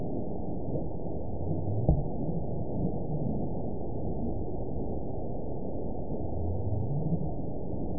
event 918035 date 04/27/23 time 14:46:51 GMT (2 years ago) score 9.48 location TSS-AB04 detected by nrw target species NRW annotations +NRW Spectrogram: Frequency (kHz) vs. Time (s) audio not available .wav